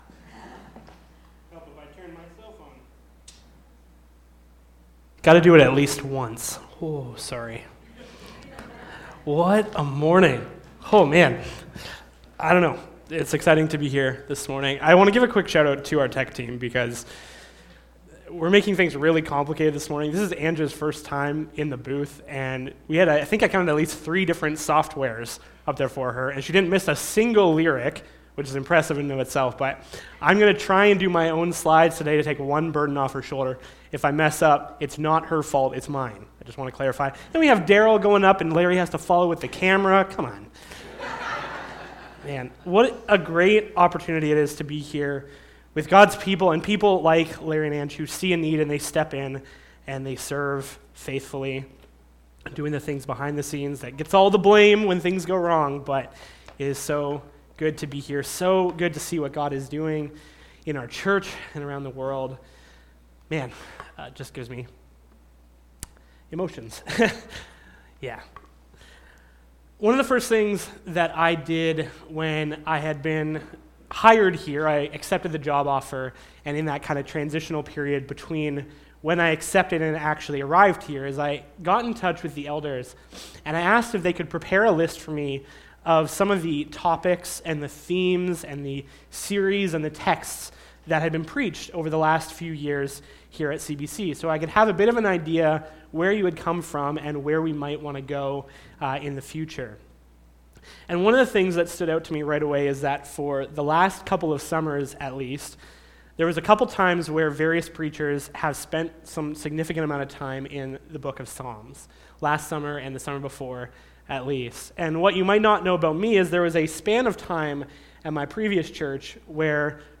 Sermon Audio and Video Which Way?